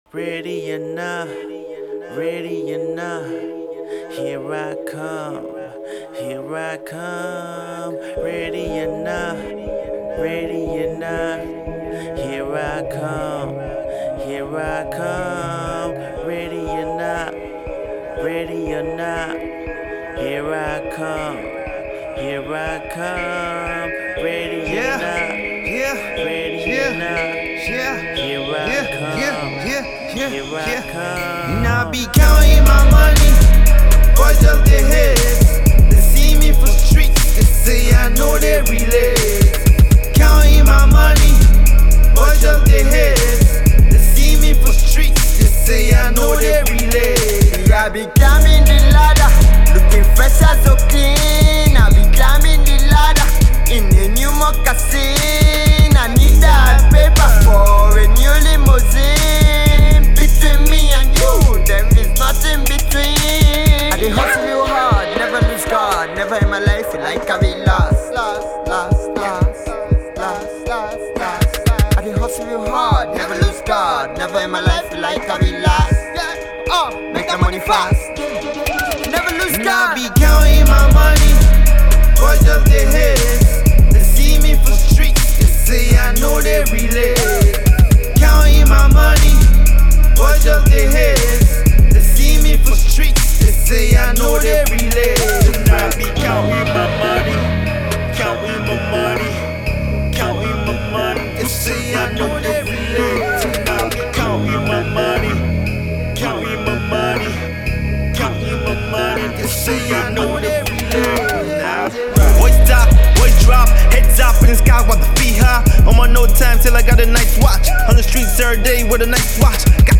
grimey instrumental